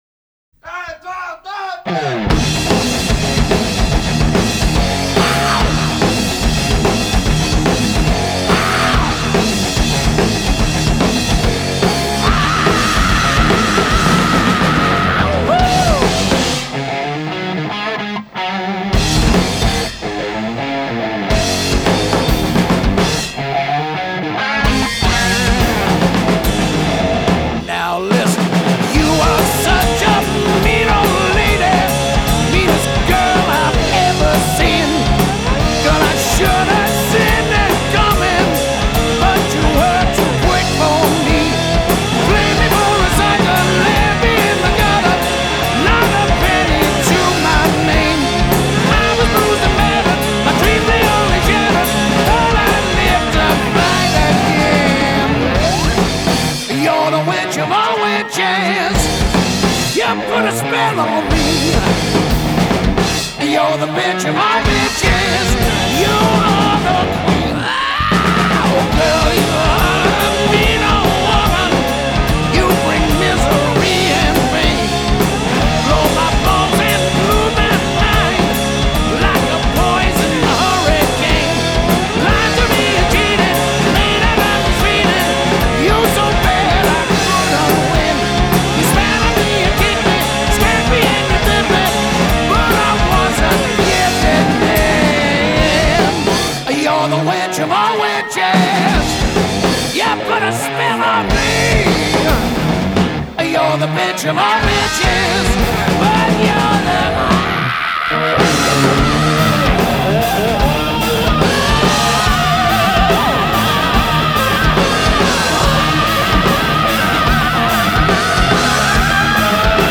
Technical Thrash/Speed Metal